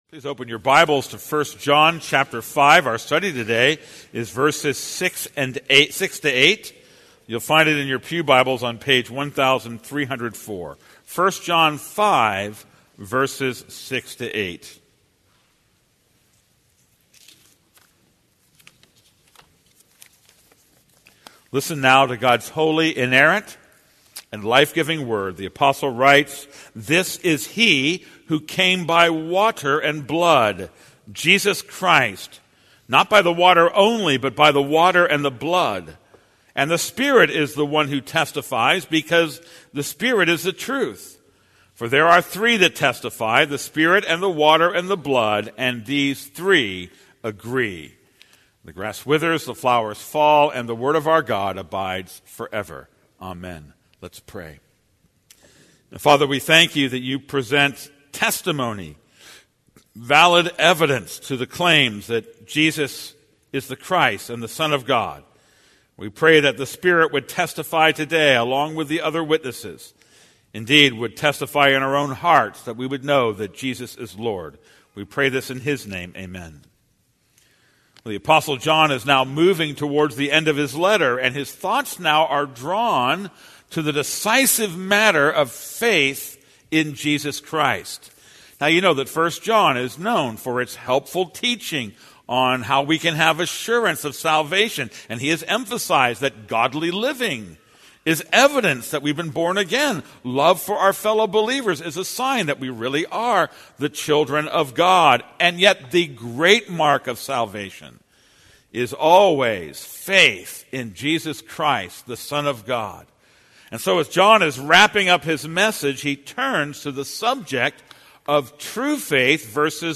This is a sermon on 1 John 5:6-8.